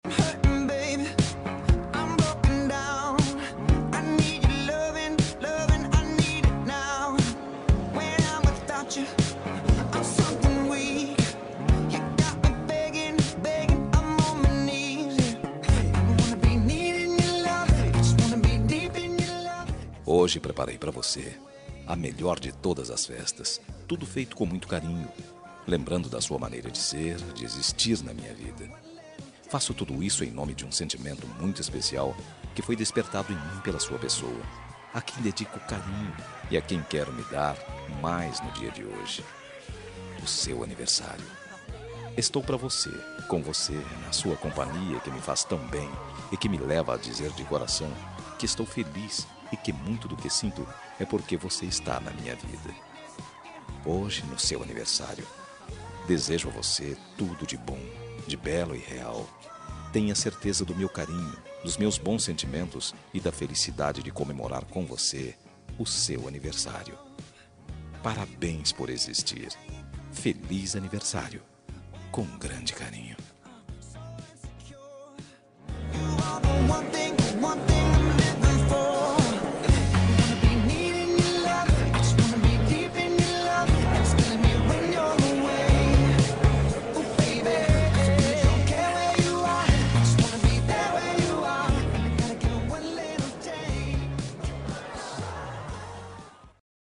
Aniversário de Ficante – Voz Masculina – Cód: 8880